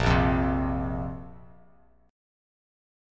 A#sus2 chord